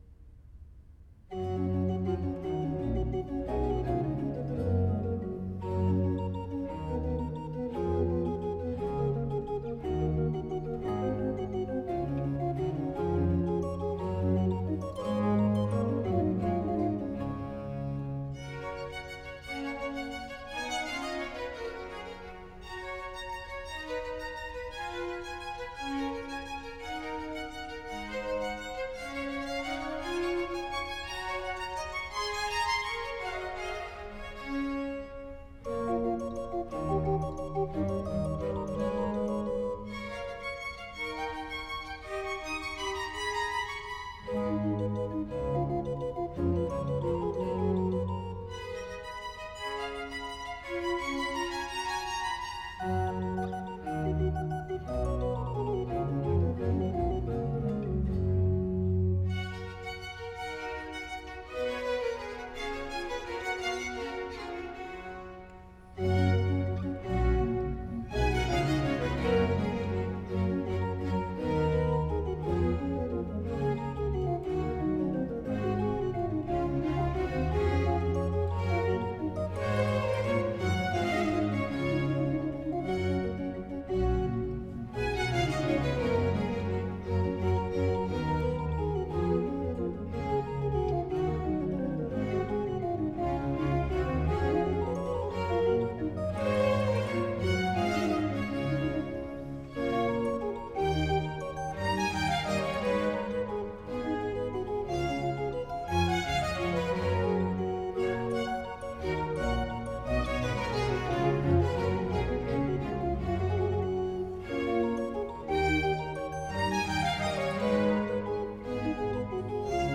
Классическая Музыка
Organ Concerto in G minor